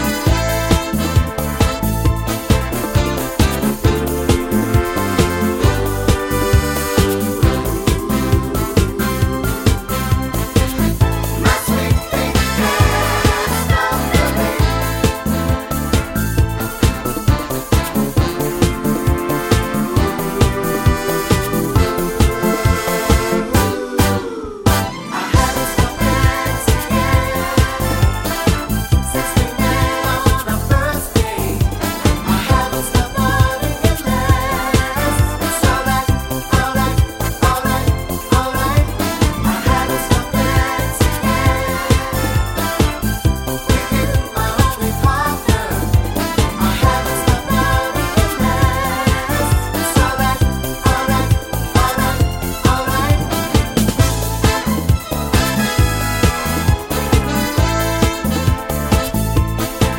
no Backing Vocals Disco 3:49 Buy £1.50